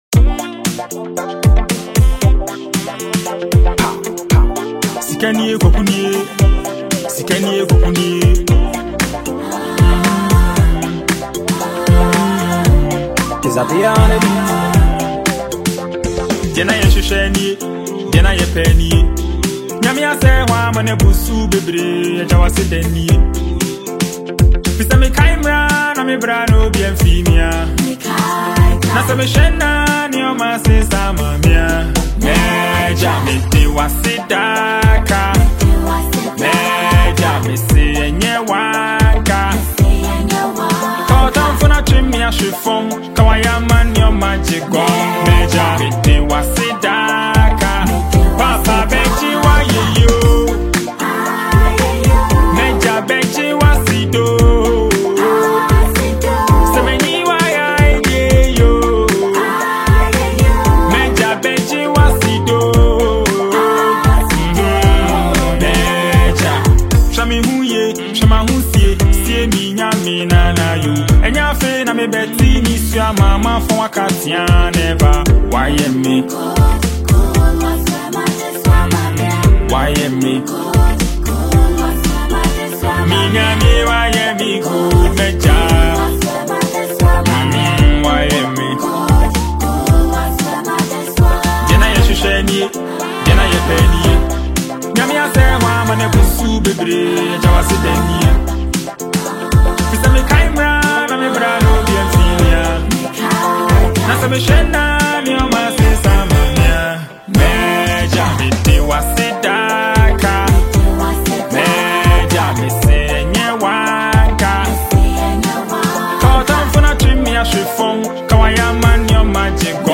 • Genre: Afrobeat / Afropop